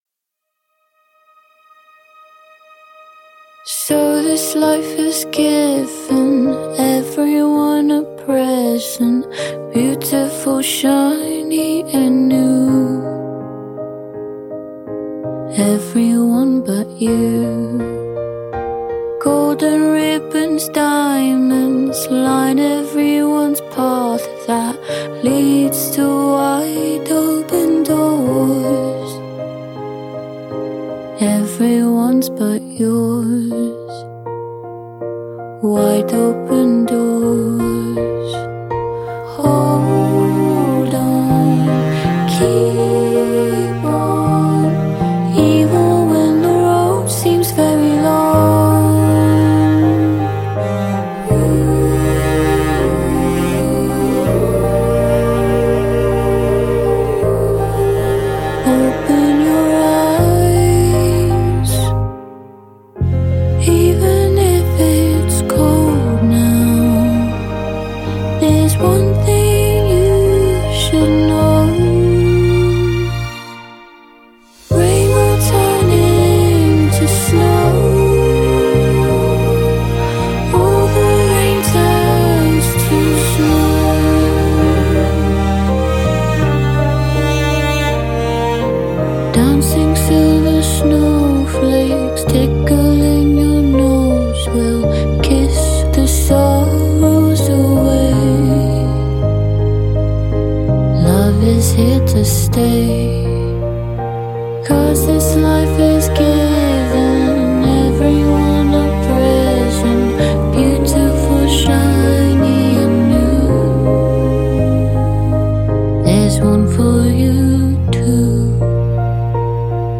u sanjivoj izvedbi